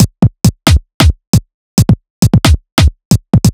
Stalk Break 135.wav